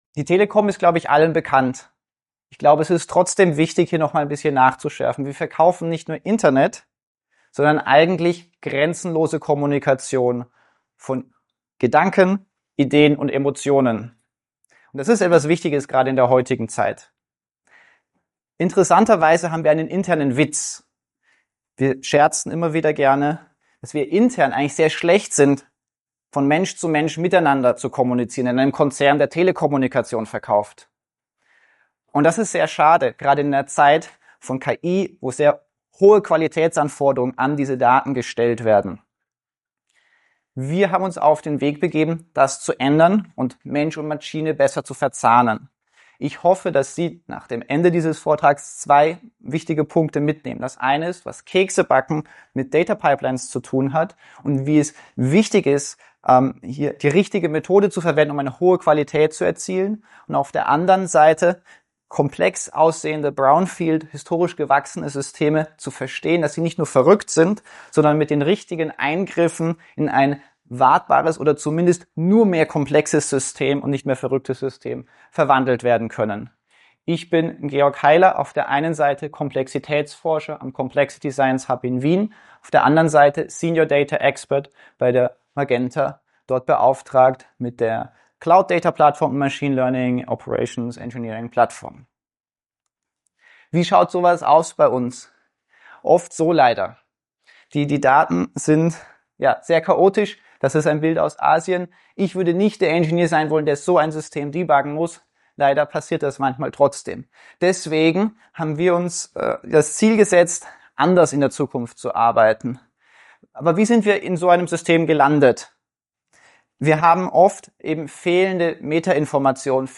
Scaling data pipelines (Big Data Minds conference Frankfurt)
presentation.mp3